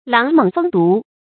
狼猛蜂毒 láng měng fēng dú
狼猛蜂毒发音
成语注音ㄌㄤˊ ㄇㄥˇ ㄈㄥ ㄉㄨˊ